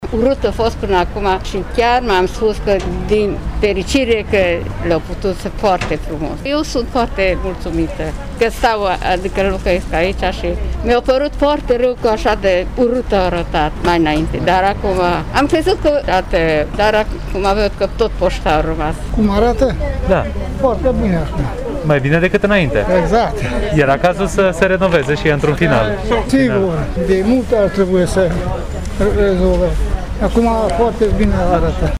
Târgumureșenii deserviți de Oficiul poștal 9 se arată mulțumiți de noua lui înfățișare: